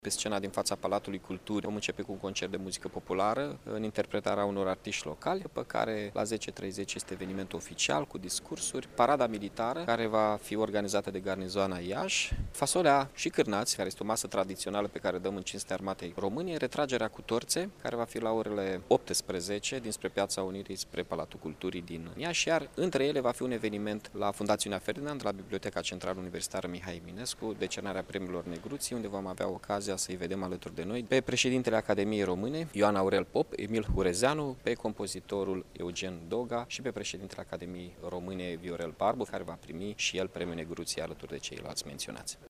Pe 1 Decembrie manifestările vor începe la ora 9.30 în faţa Palatului Culturii, după cum a precizat primarul Mihai Chirica.